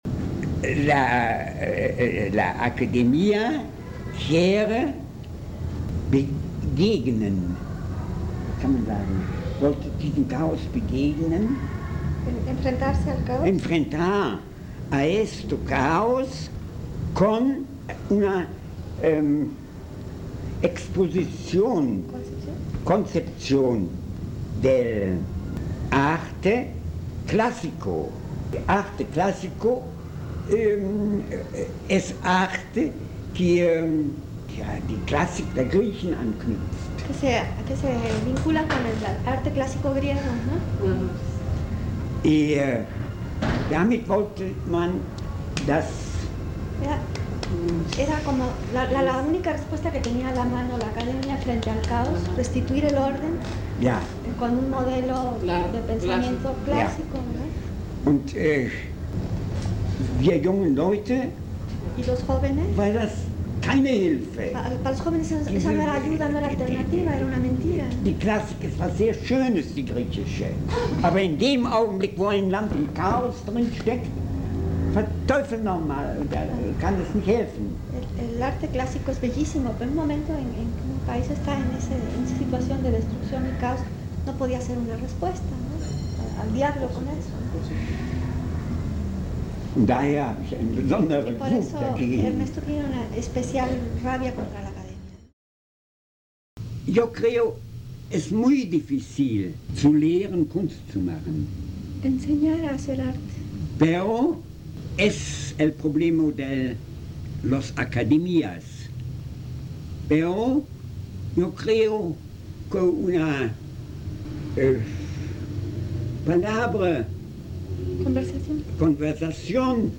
Las grabaciones se realizaron en el taller de pintura con jóvenes del barrio de Tepito, en la Casa de Cultura Enrique Ramírez y Ramírez, en el año de 1984.